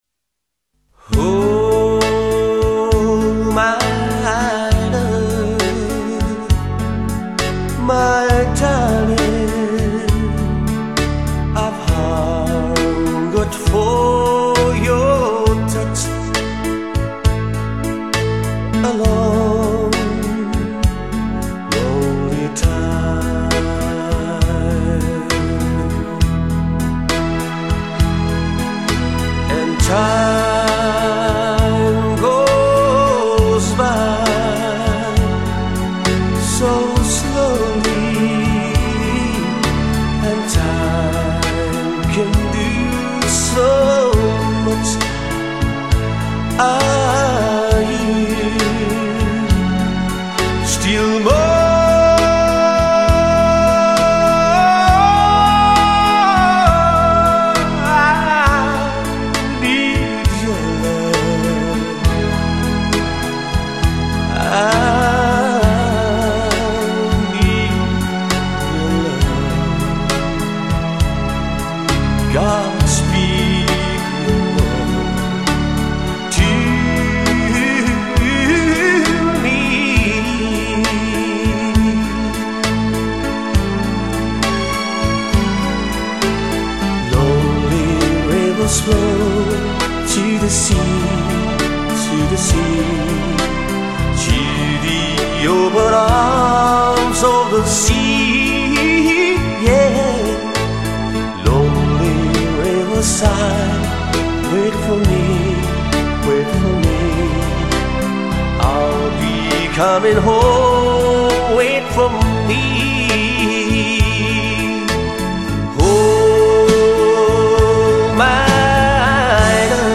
语    种：纯音乐
低吟浅唱，有如天籁，直触人心，荡埃涤尘。木吉他的朴素和弦，民谣歌手的真情演绎，帮我们寻回人类的童真，生活的安宁。